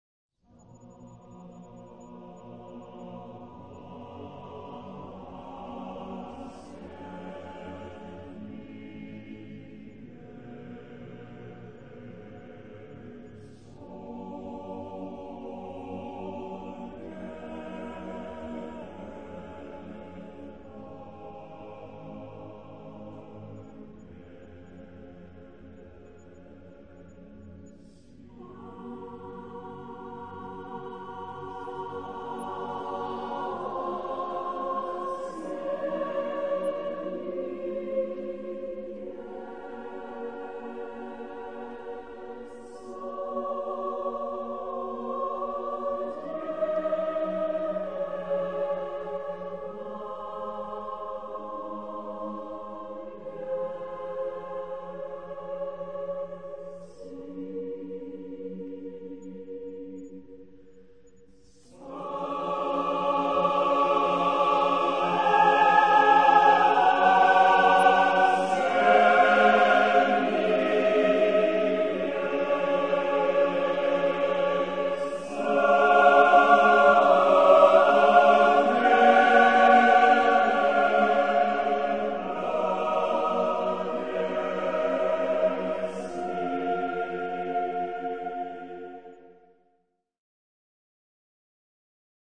SATTBB (6 voix mixtes) ; Partition complète.
Hymne (sacré).
Orgue (1) ad lib
ré majeur ; si mineur